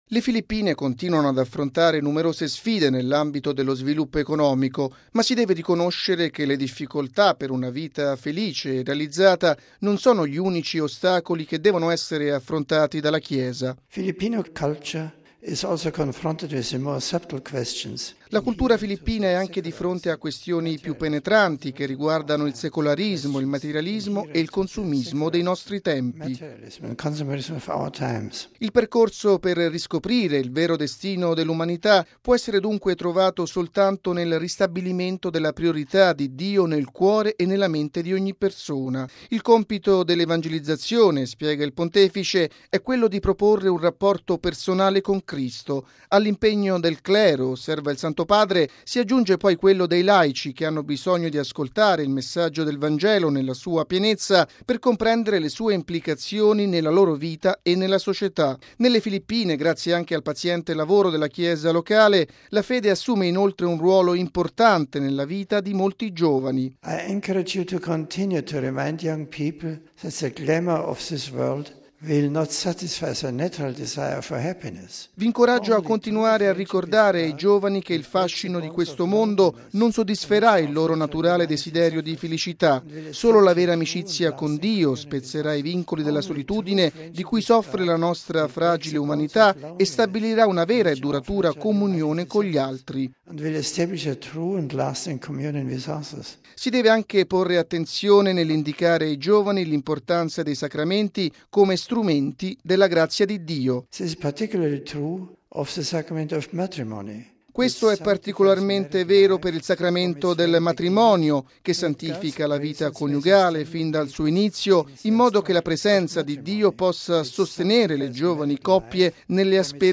E’ quanto ha affermato stamani Benedetto XVI rivolgendosi ai vescovi della Conferenza Episcopale delle Filippine in Visita ad Limina. Il Papa si è anche soffermato sulle sfide che la Chiesa delle Filippine deve affrontare nel presente.